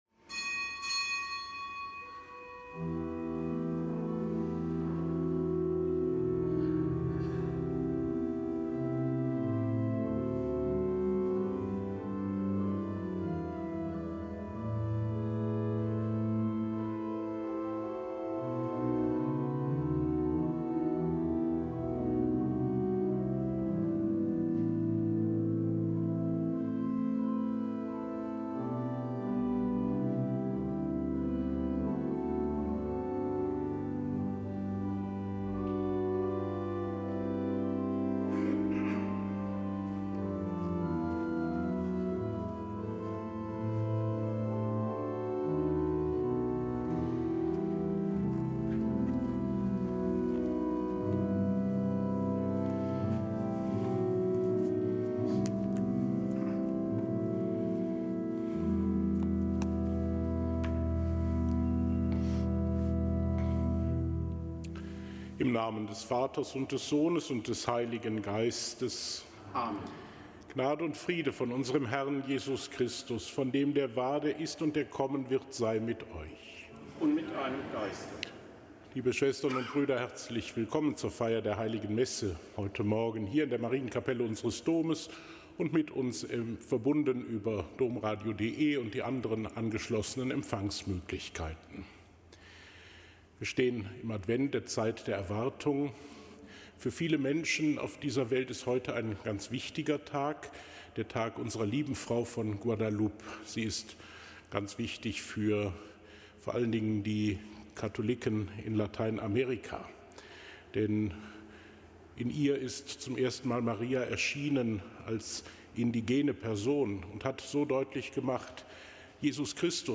Kapitelsmesse aus dem Kölner Dom am Freitag der zweiten Adventswoche. Nichtgebotener Gedenktag Unserer Lieben Frau von Guadalupe.